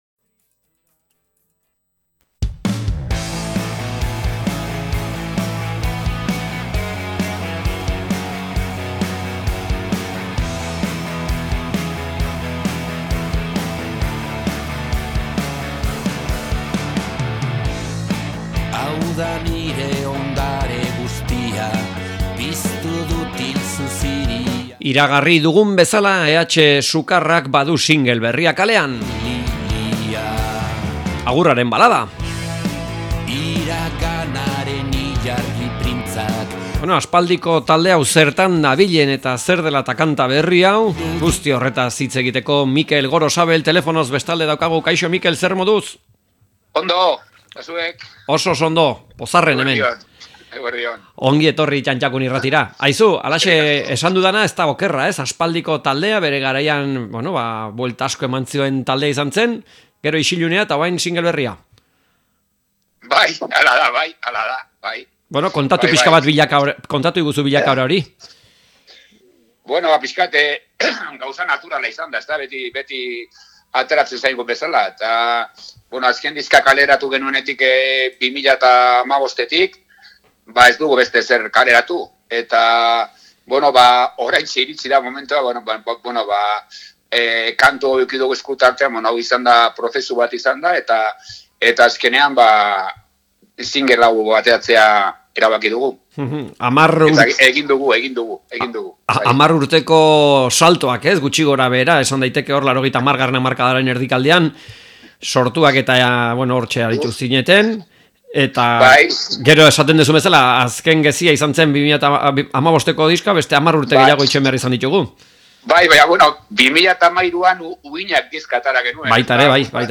EH Sukarrari elkarrizketa
Elkarrizketak